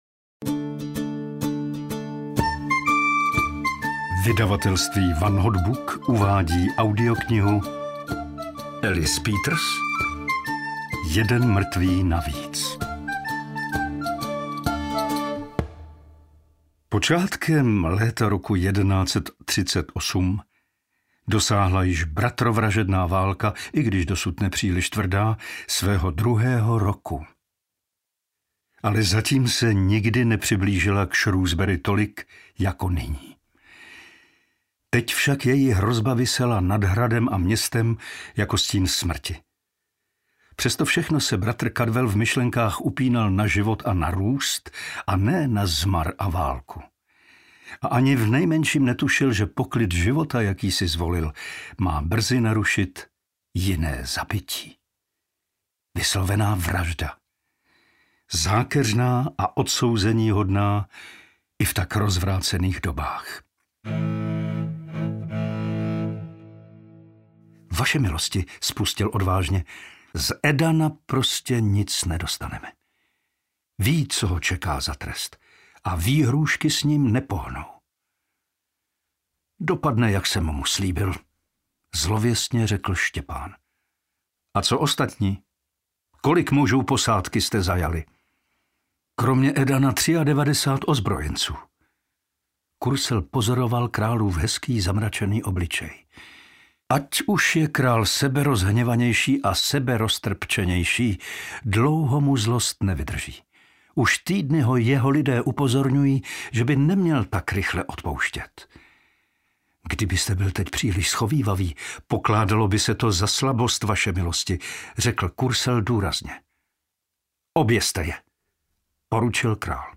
Jeden mrtvý navíc audiokniha
Ukázka z knihy